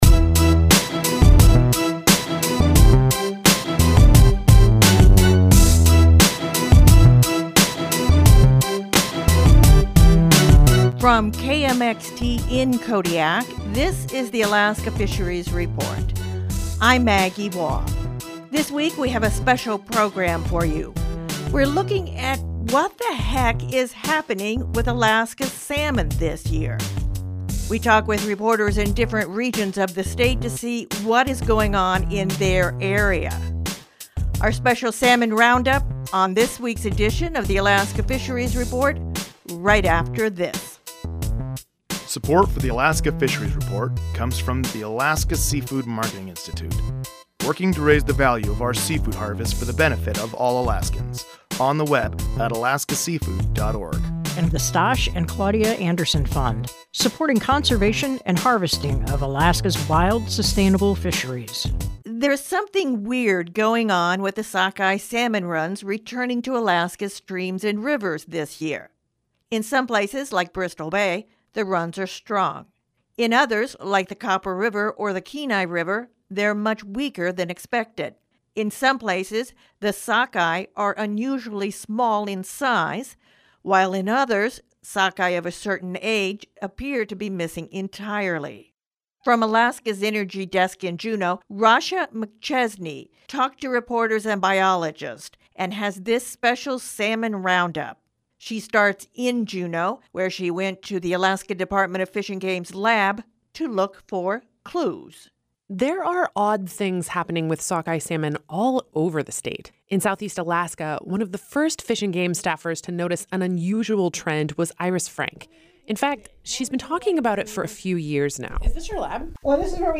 We talk with reporters in different regions of the state to see what’s doing with their area.